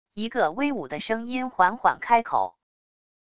一个威武的声音缓缓开口wav音频